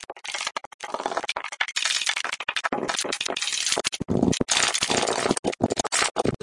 拨浪鼓声音集 " F11D
描述：一行嘈杂和颗粒状的声音元素，取自木质声源，而是鼓声。
标签： 颗粒 噪声 拨浪鼓 木材
声道立体声